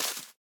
Minecraft Version Minecraft Version latest Latest Release | Latest Snapshot latest / assets / minecraft / sounds / block / azalea_leaves / break7.ogg Compare With Compare With Latest Release | Latest Snapshot
break7.ogg